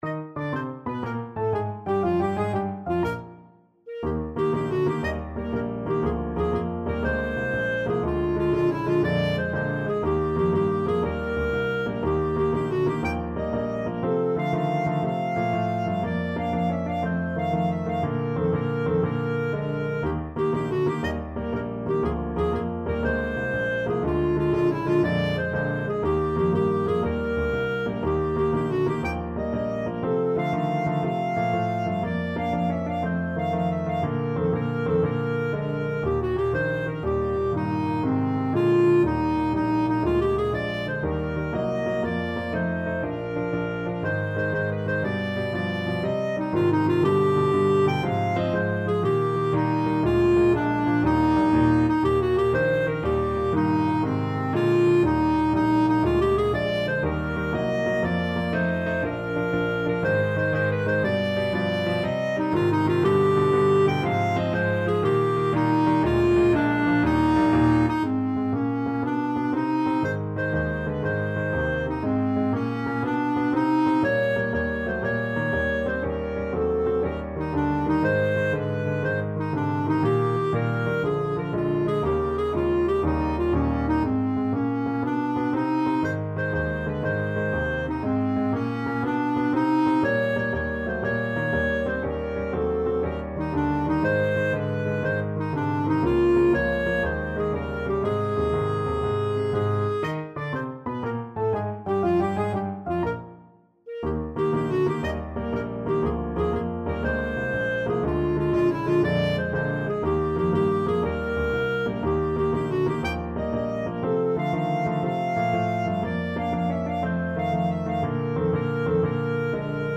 ClarinetPiano
Allegro con brio . = 120 (View more music marked Allegro)
6/8 (View more 6/8 Music)
Clarinet  (View more Intermediate Clarinet Music)
Classical (View more Classical Clarinet Music)
Marching Music for Clarinet